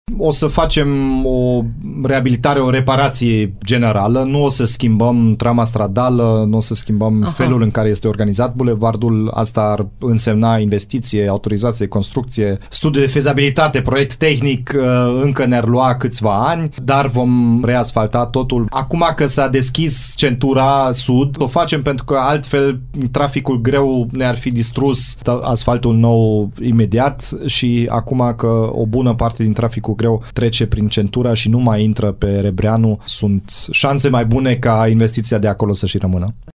Deschiderea circulației pe centura de Sud permite acum reparațiile la carosabil pe toată lungimea bulevardului, spune primarul Dominic Fritz.